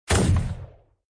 Tank_Attack.mp3